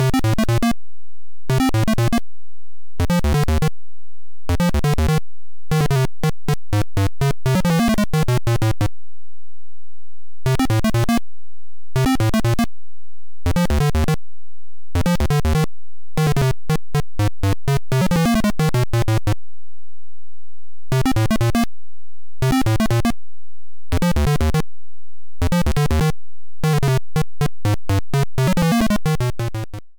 Self-captured from the Sharp X1 version